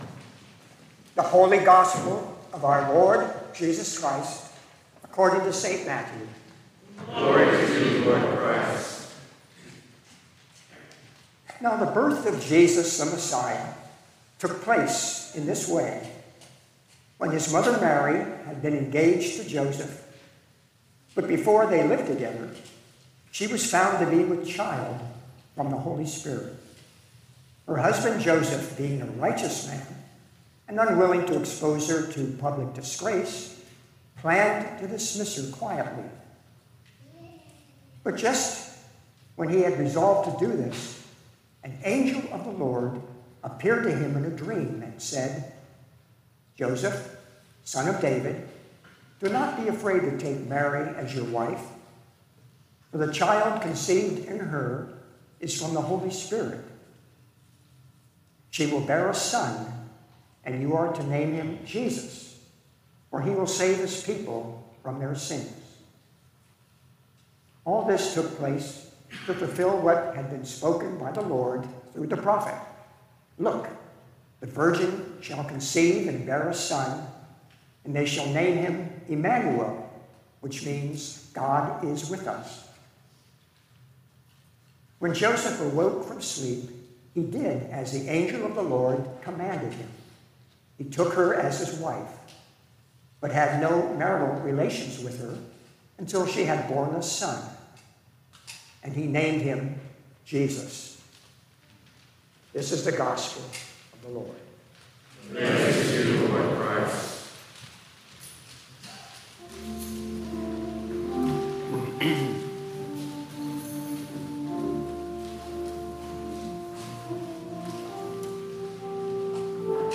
Latest Sermons & Livestreams